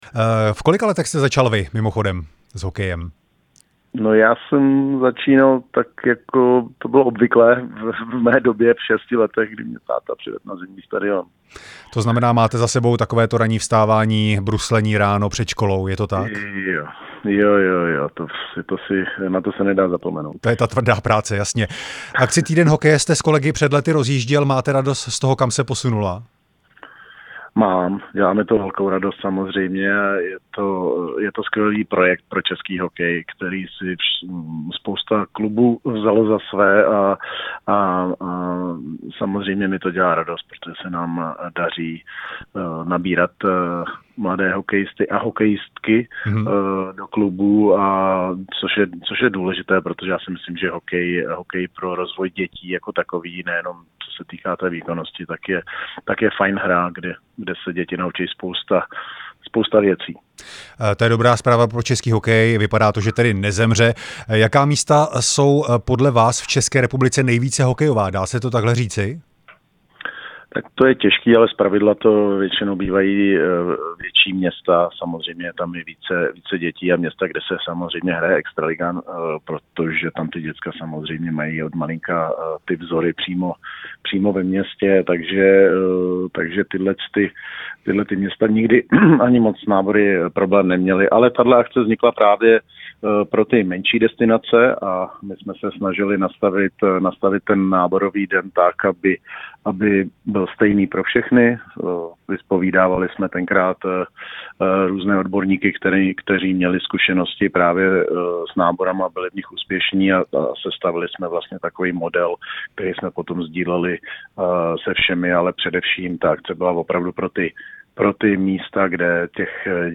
Rozhovor s bývalým hokejistou Milanem Hniličkou